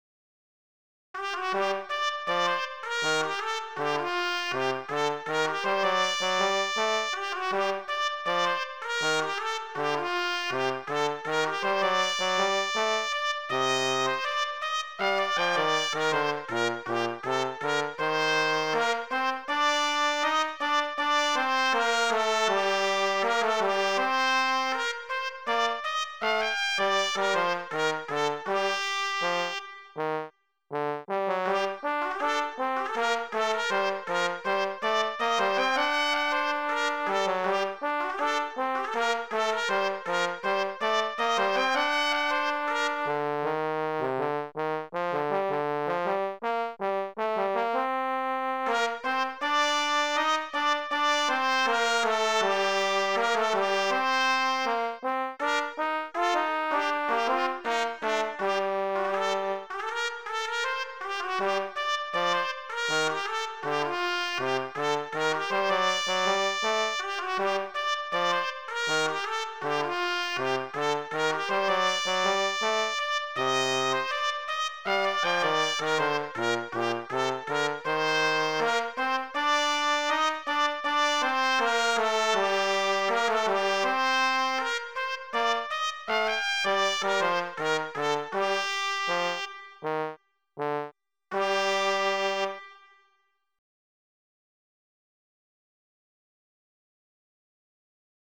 a traditional melody